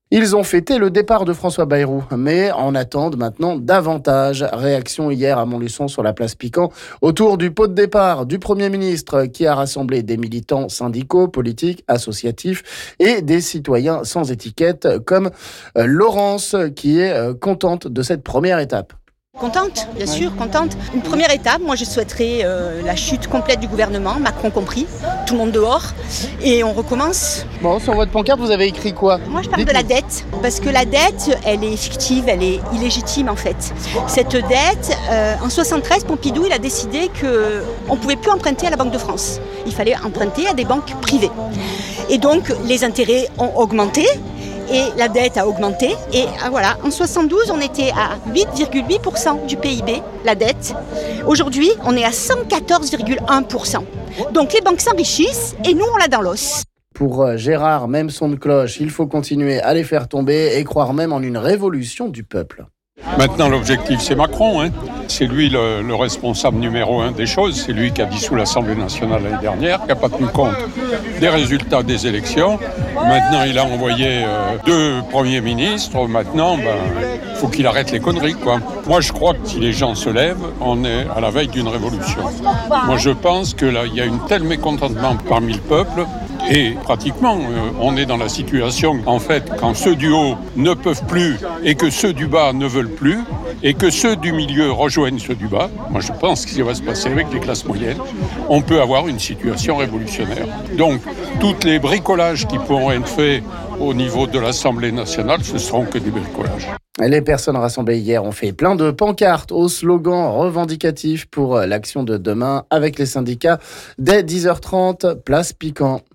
Témoignages ici...